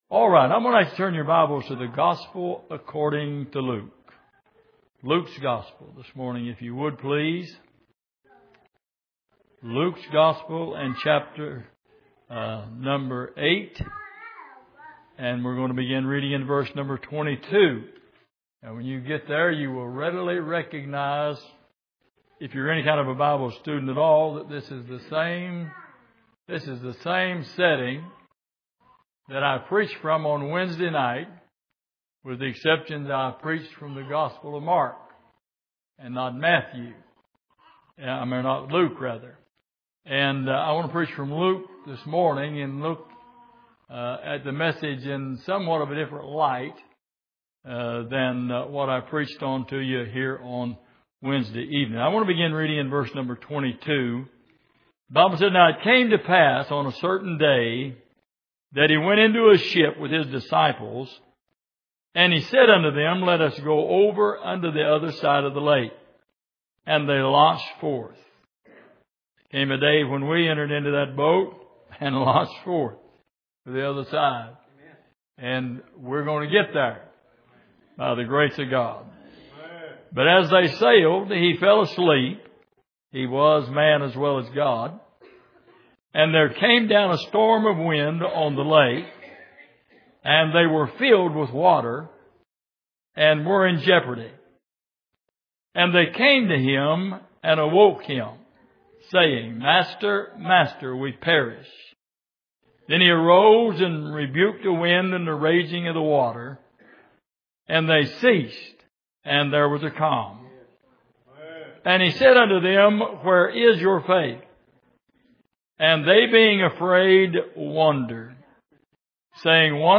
Passage: Luke 8:22-25 Service: Sunday Morning